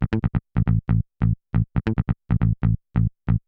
E BASS    -L.wav